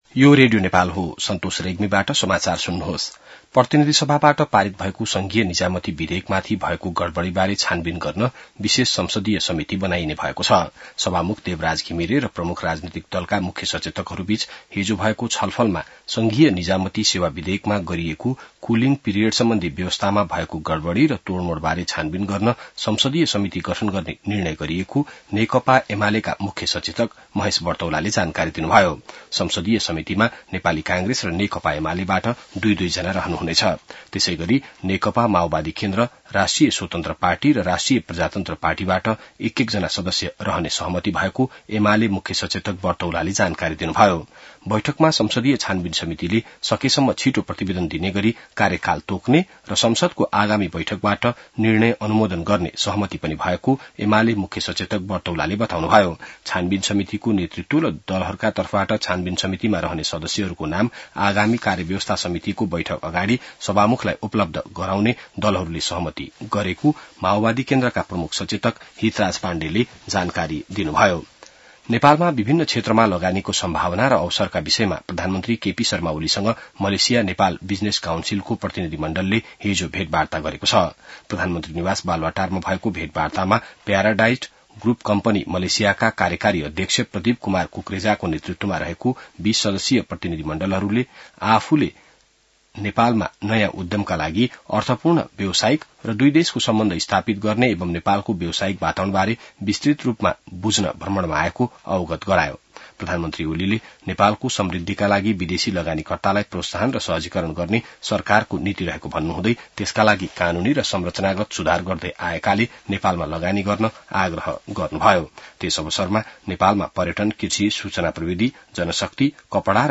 बिहान ६ बजेको नेपाली समाचार : २२ असार , २०८२